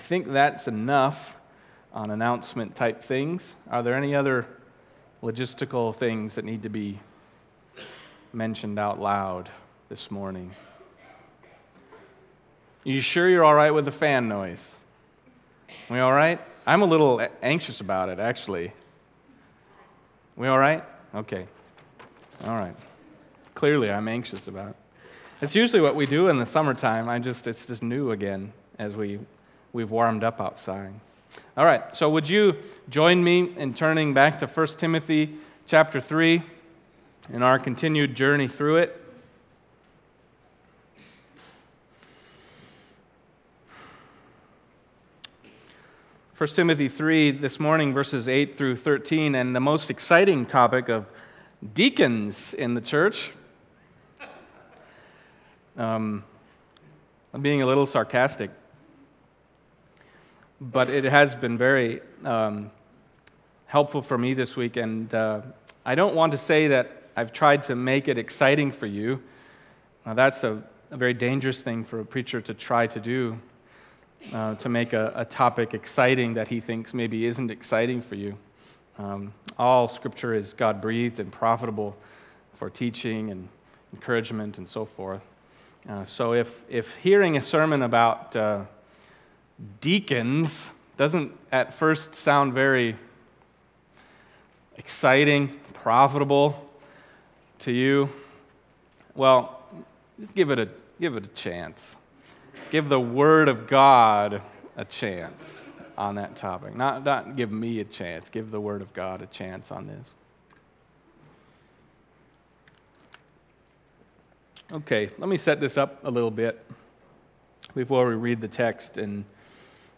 Passage: 1 Timothy 3:8-13 Service Type: Sunday Morning